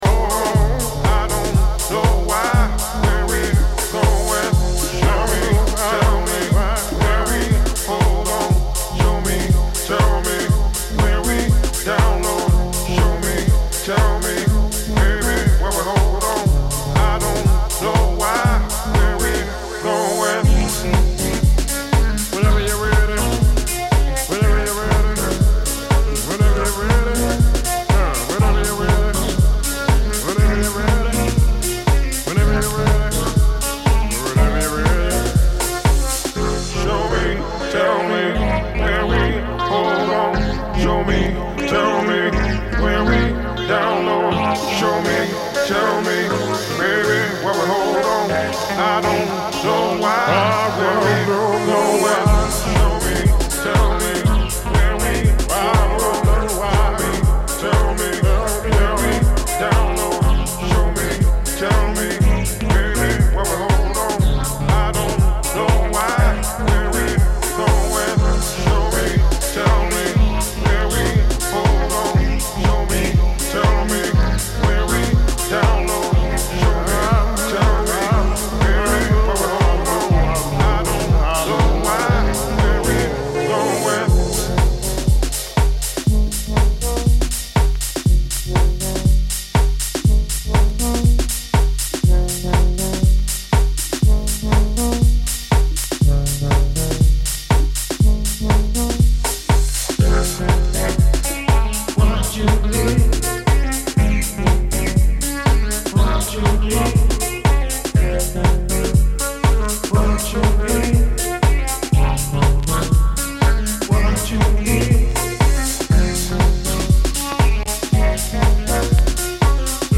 In der Sendung werden neben Neuerscheinungen aus der weiten Welt der Housemusik vor allem die Tracks jener Musikrichtung vorgestellt, die in den vergangenen Jahren nur kaum oder wenig Beachtung fanden. Au�erdem pr�sentieren wir dem H�rer unsere aktuellen Ausgeh-Tipps f�r das Wochenende und einen kurzen DJ-Mix.